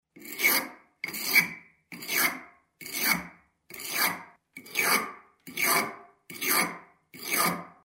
Звуки заточки ножа
На этой странице представлены звуки заточки ножа в разных вариациях: от резких металлических скрежетов до монотонных ритмичных движений.
Точильным камнем натачивают нож